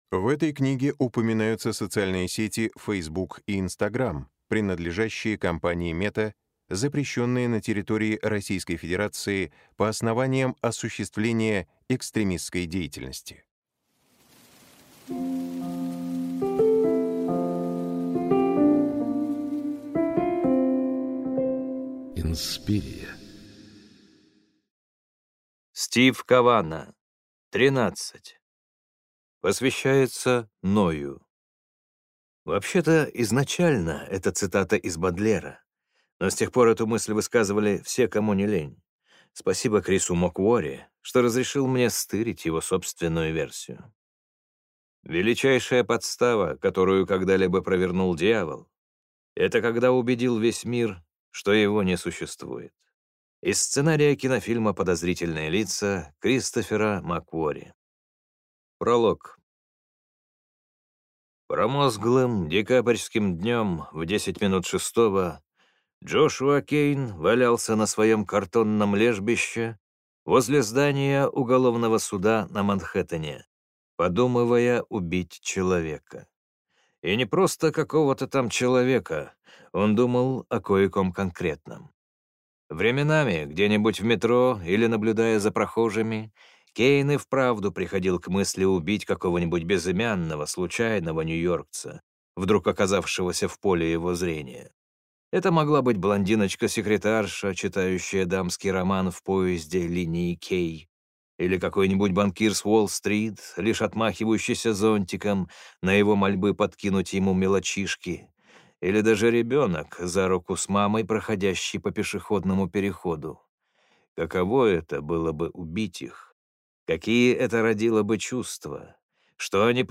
Аудиокнига Тринадцать | Библиотека аудиокниг